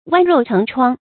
剜肉成疮 wān ròu chéng chuāng
剜肉成疮发音